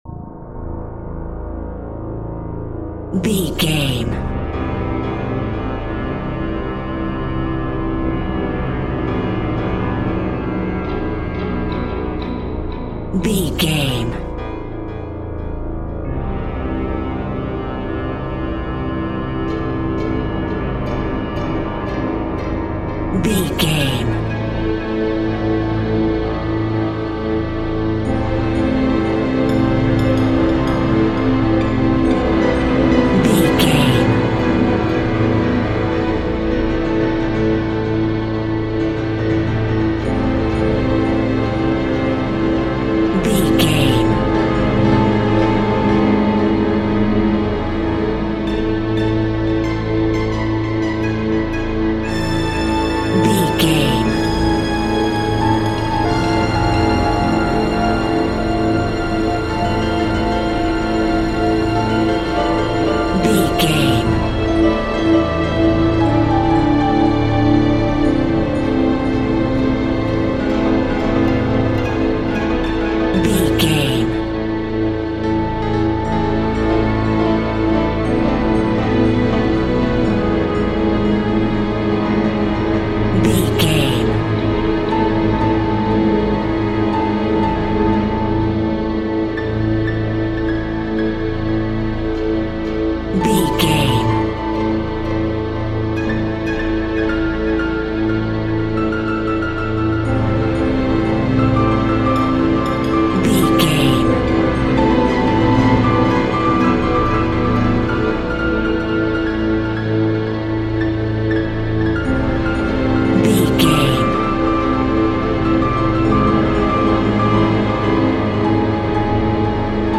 Aeolian/Minor
tension
ominous
dark
haunting
eerie
piano
strings
synthesizer
ambience
pads